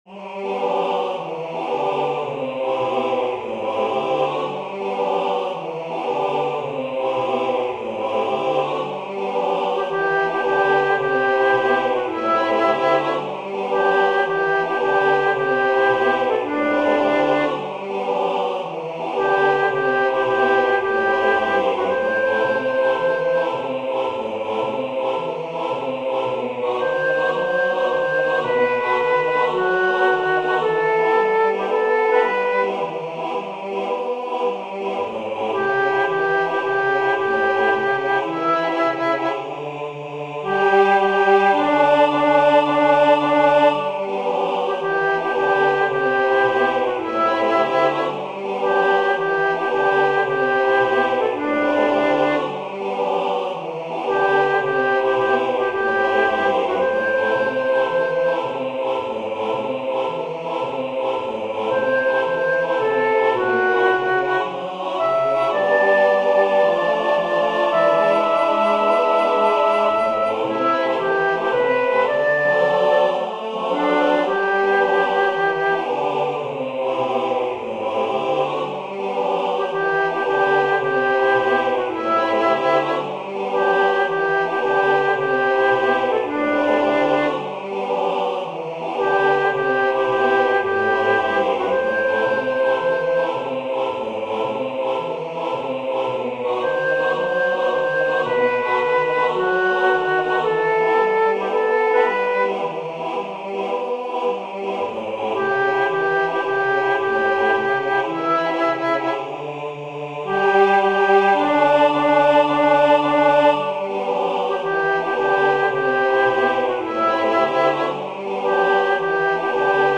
Formación:SATB
Género:American Standard